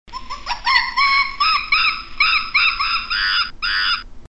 • Качество: высокое
Крик обезьяны в джунглях